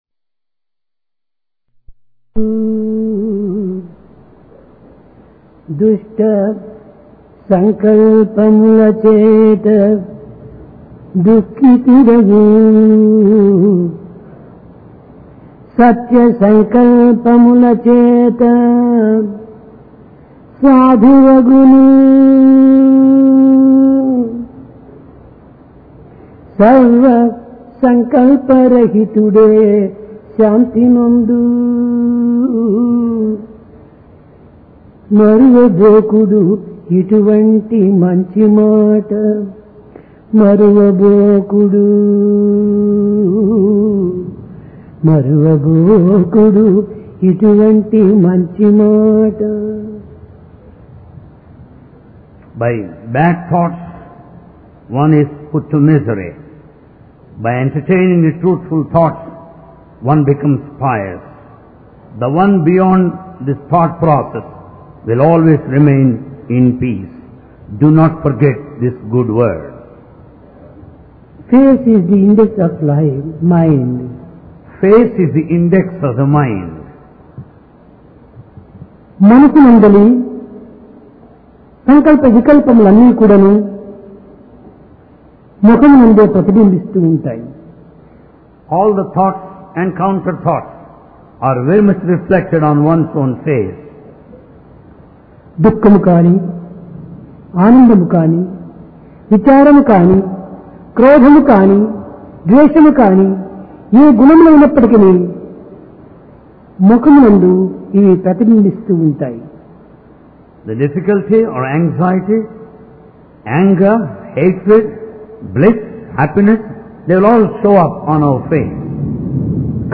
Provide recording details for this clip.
Sai Darshan Home Date: 6 Jul 1996 Occasion: Divine Discourse Place: Prashanti Nilayam Mind - The Cause For Liberation And Attachment Face is the index of the mind.